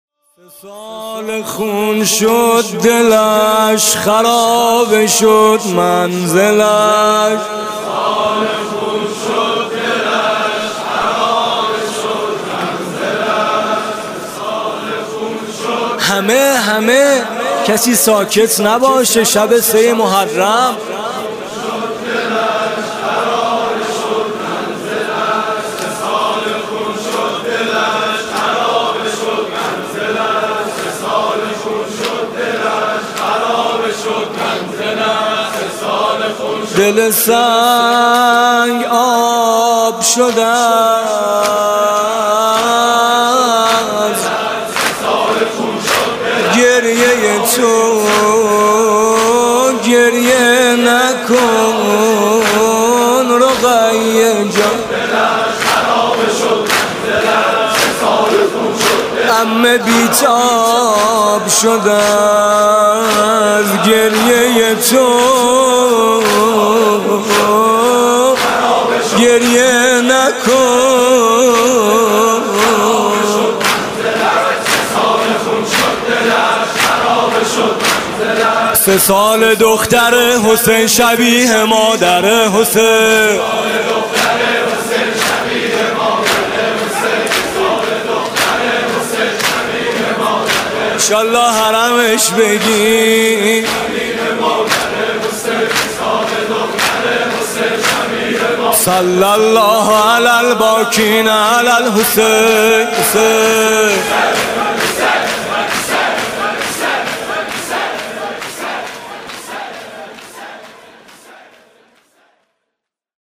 گلچینی از مداحی ویژه شب اول محرم